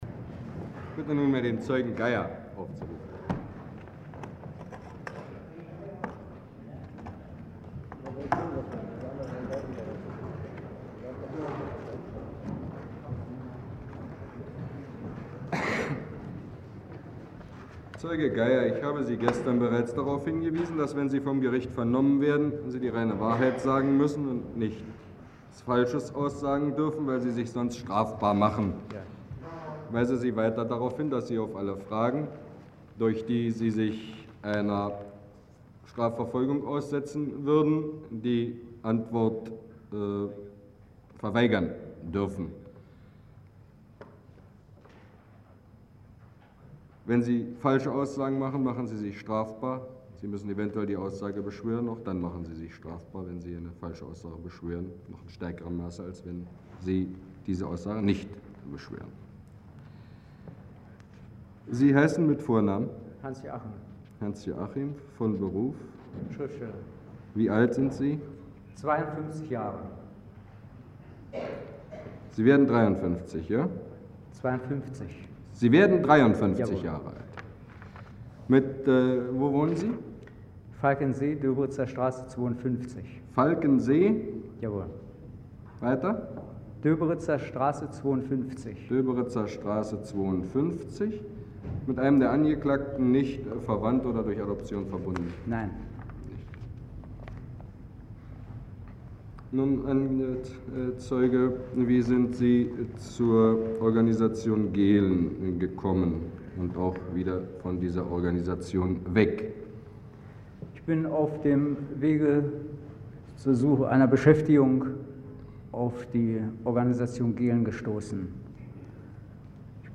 Zeugenvernehmung
Die Prozessaufnahme ist als Tonband in der Hauptabteilung IX des MfS ( HA IX ) im Archiv des BStU überliefert. Wahrscheinlich handelt es sich dabei um die Kopie einer Aufnahme des DDR -Rundfunks.